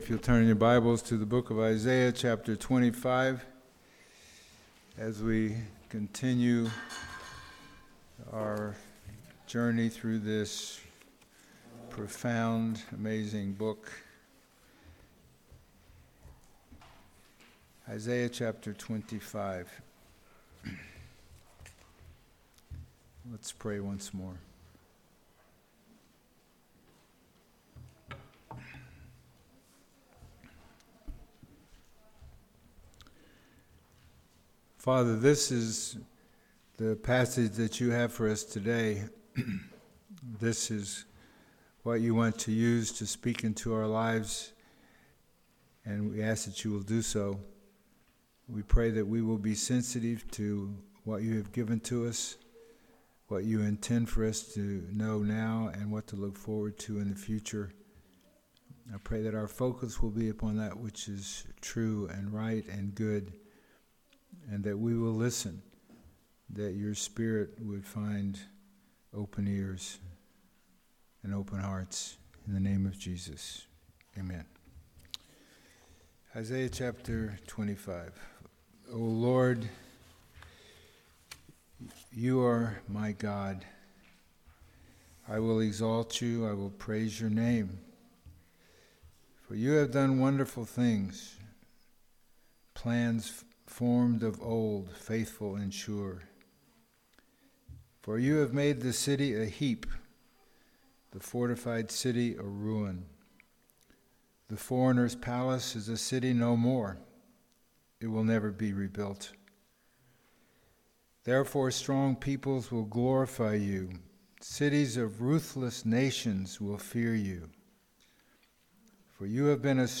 Sermons | The Bronx Household of Faith
Service Type: Sunday Morning